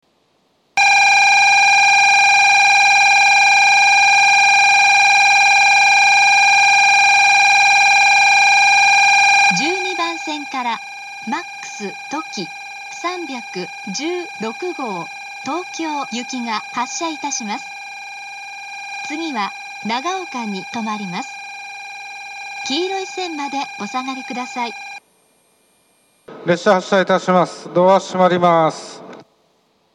１２番線発車ベル Ｍａｘとき３１６号東京行の放送です。